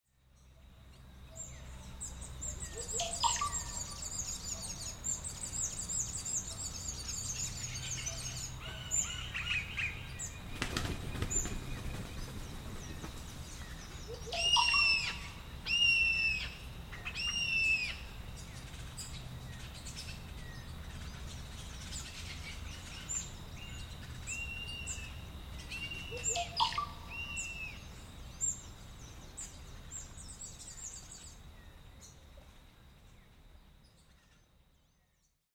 In Colombia’s lush coffee-growing region, mornings are a celebration of life and sensory delight. Perched on misty mountain slopes, the aroma of freshly brewed coffee mingles with the melodic calls of toucans, hummingbirds, and the occasional eagle gliding effortlessly above.